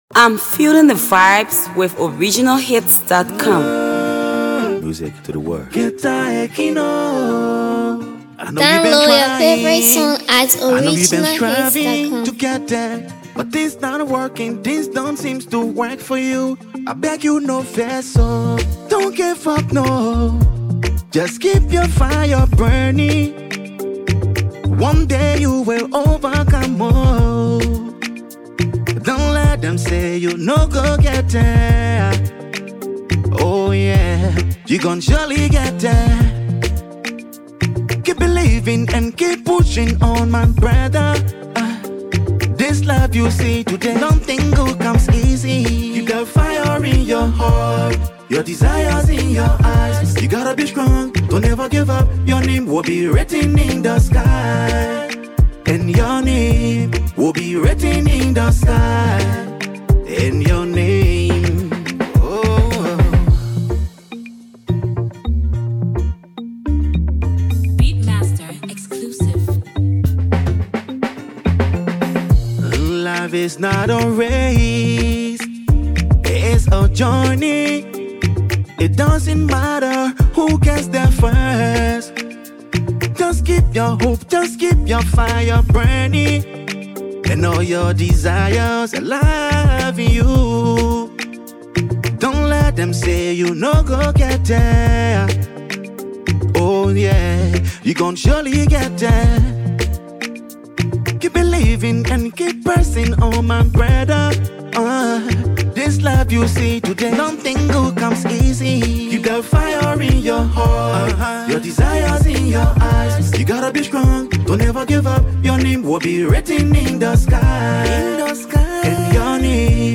Liberian artist
This latest studio effort
Afro